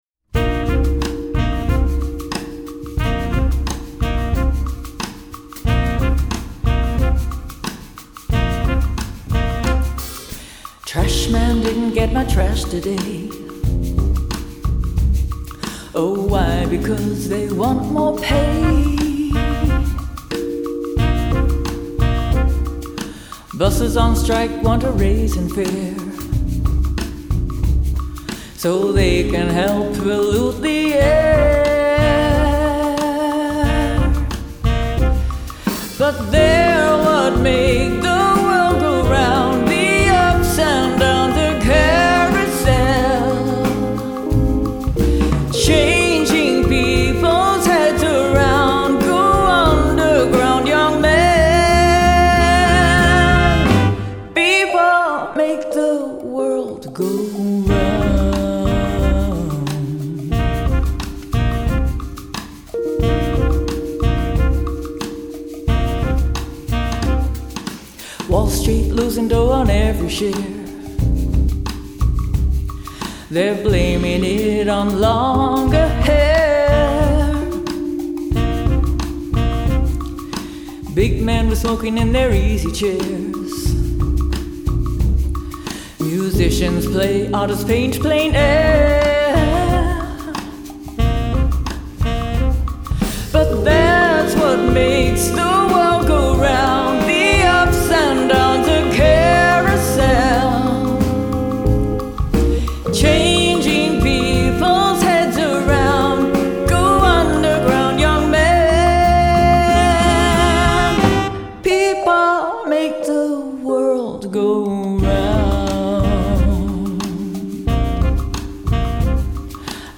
FILE: Jazz Vox